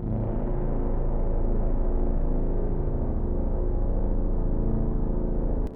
Orchestra